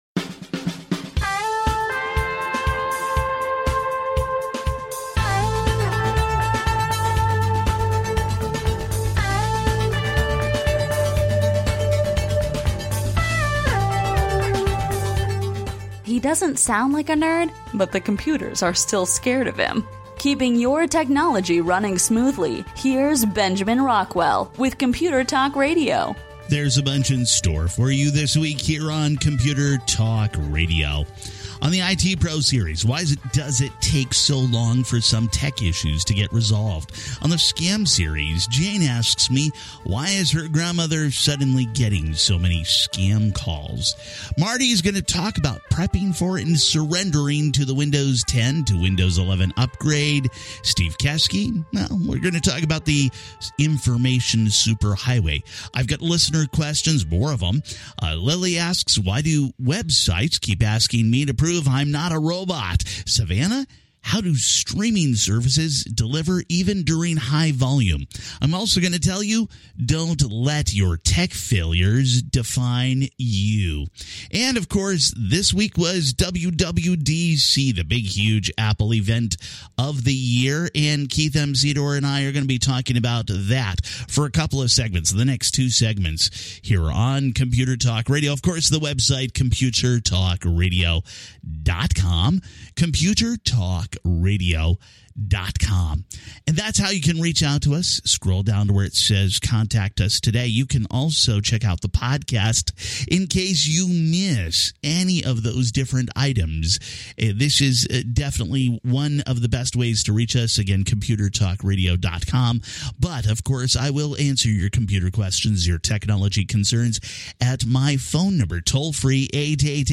Computer Talk Radio is a nationally syndicated broadcast radio program on computers and technology, and how they impact your life.